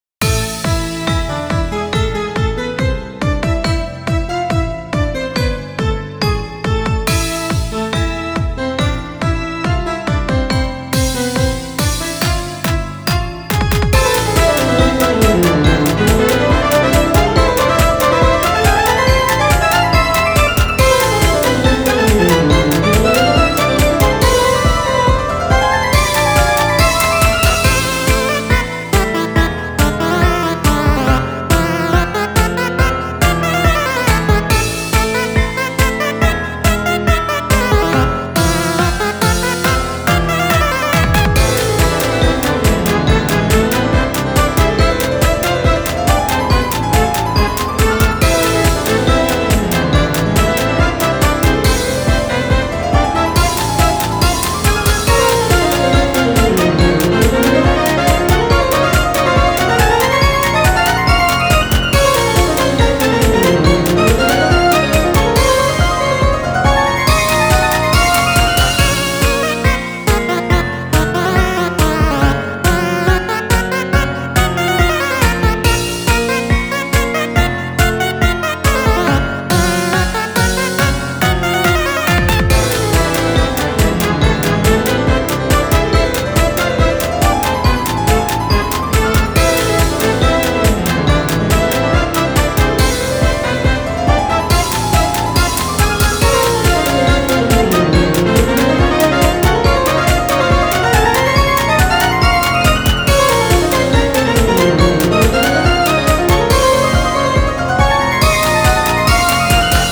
MUSIC -戦闘向け-
ogg版   かわいげのある邪悪さ。